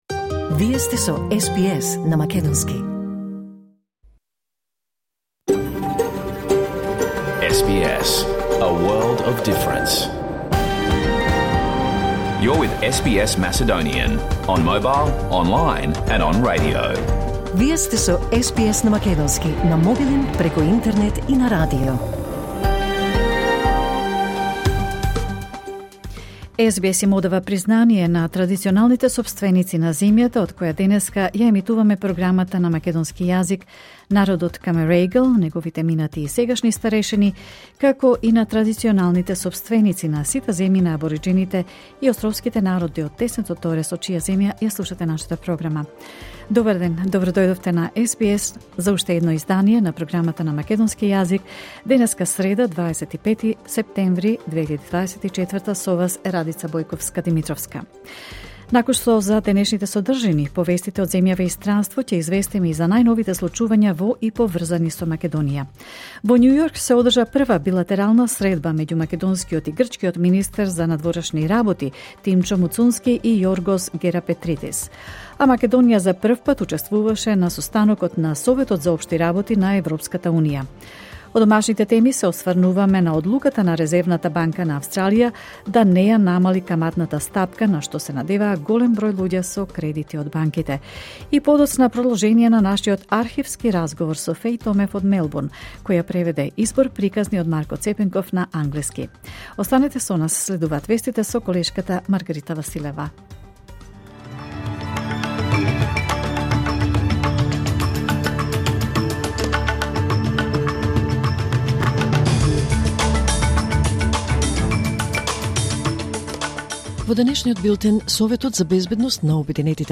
SBS Macedonian Program Live on Air 25 September 2024